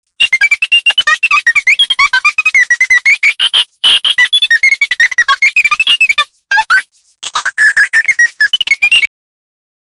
Cute little robot laugher
cute-little-robot-laugher-5xwgkkg3.wav